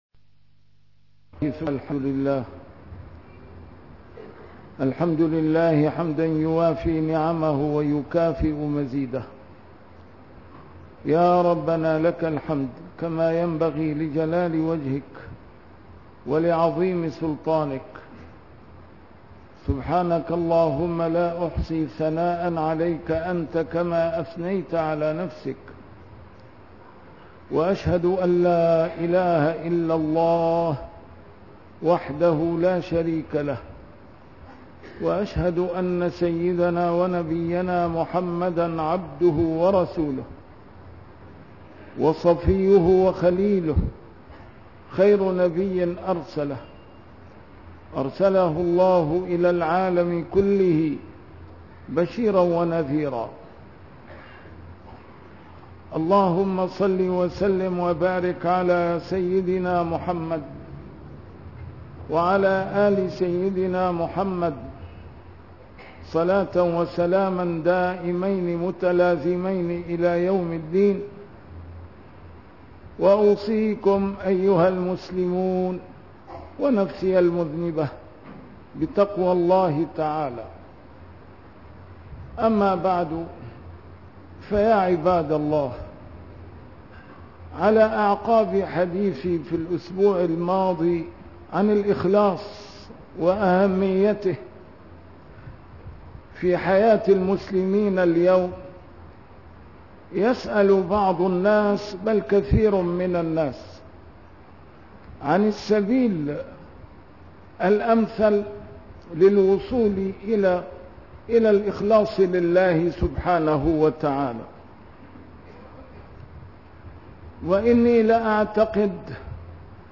A MARTYR SCHOLAR: IMAM MUHAMMAD SAEED RAMADAN AL-BOUTI - الخطب - وهل يسأل مؤمن بالله كيف أخلص للهّ!!